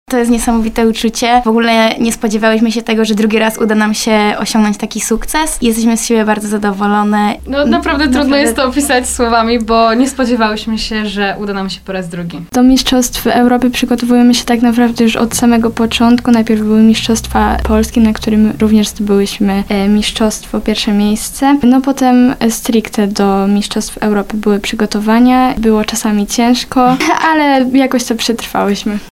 Obecne w studiu Radia RDN Małopolska